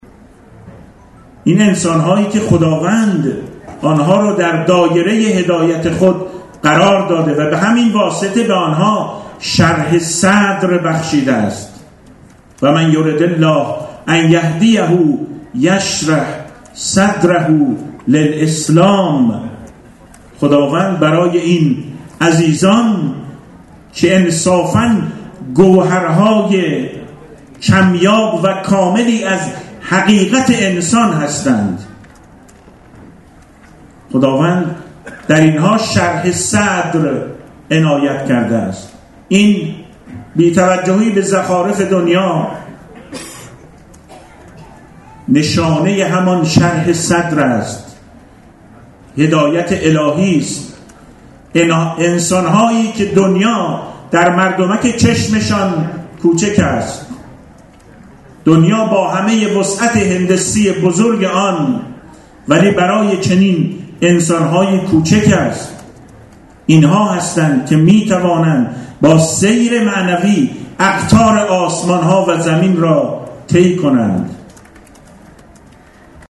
به گزارش خبرنگار سرویس فرهنگی و اجتماعی خبرگزاری رسا، سردار حسین سلامی جانشین فرمانده کل سپاه پاسداران در ششمین "همایش ملی سلامت معنوی اسلامی" که امروز در سالن همایش‌های غدیر قم برگزار شد، با اشاره به سابقه مبارزاتی دکتر شیبانی اظهار داشت: دکتر شیبانی کسی است که سال‌ها بیرق آزادی را در زندان‌ها بر دوش می‌کشید تا رسالتی نبوی برای باز کردن غل و زنجیر اسارت از دست و پای یک ملت را به فرجام برساند.